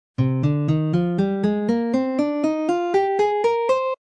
Aeolian mode has a minor tonality and is enharmonic to a natural minor scale.
C Aeolian scale pattern #4
c-aeolian-mode-scale-pattern-4.mp3